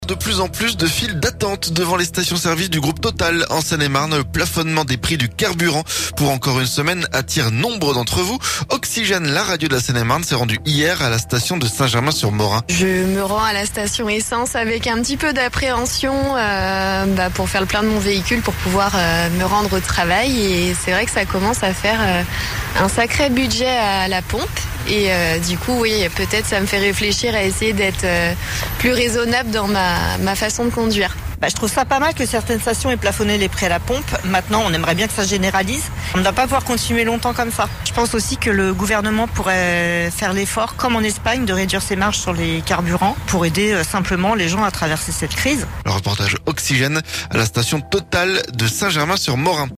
CARBURANTS - Reportage dans une station-service de Seine-et-Marne
De plus en plus de files d'attente devant les stations-service du groupe Total en Seine-et-Marne. Le plafonnement des prix du carburant pour encore une semaine attire nombre d'entre vous. Oxygène, la radio de la Seine-et-Marne s'est rendu à la station de Saint-Germain-sur-morin.